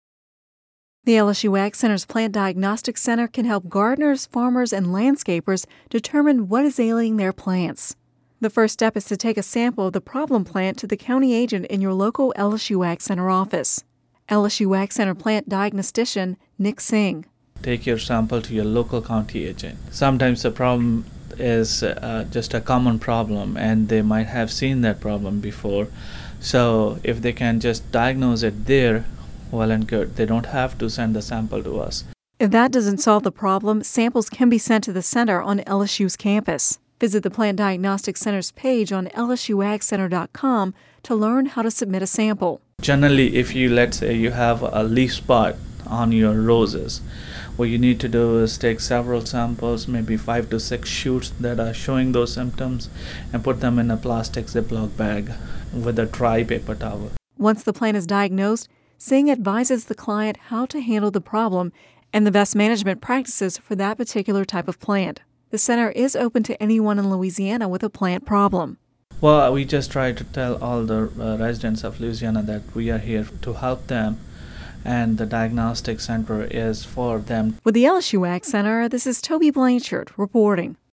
(Radio News 03/21/11) The LSU AgCenter’s Plant Diagnostic Center can help gardeners, farmers and landscapers determine what is ailing their plants. The first step is to take a sample of the problem plant to the county agent in your local LSU AgCenter office.